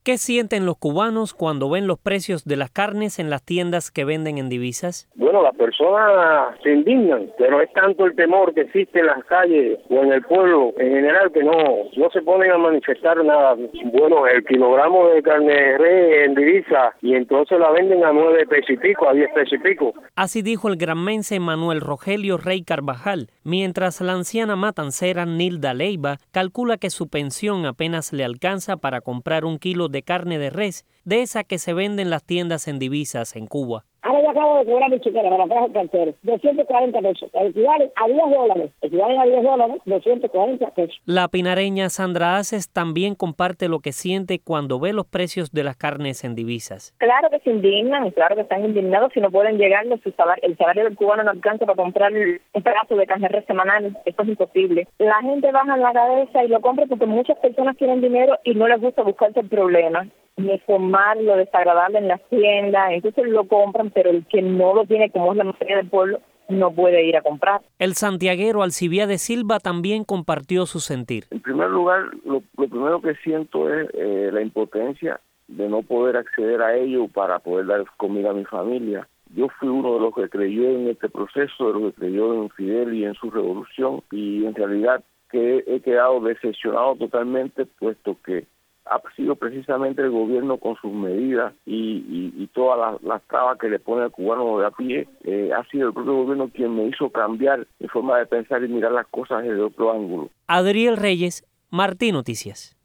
La compra de carne es un lujo que pocos se pueden dar en la isla, dijeron cubanos consultados por Martí Noticias que aseguran sentir indignación e impotencia cuando la ven en las tiendas por divisas a un precio exhorbitante.